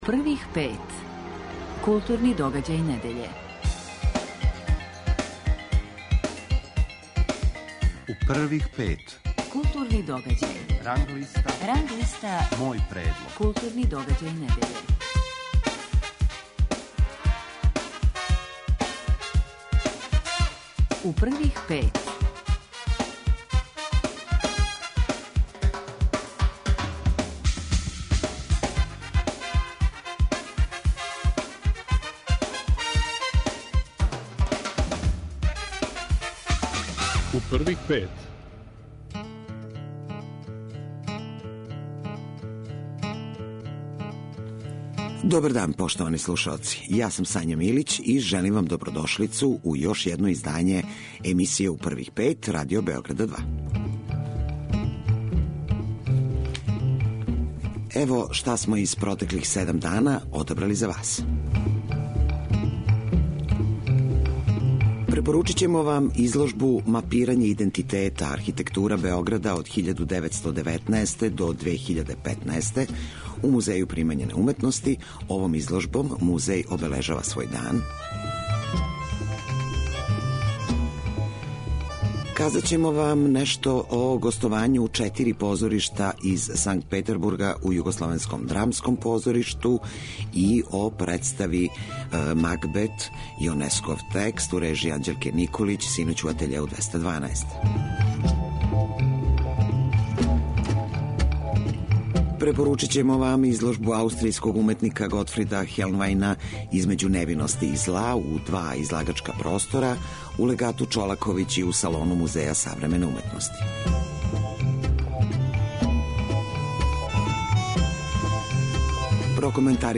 Гост емисије биће Владислав Бајац, писац и издавач.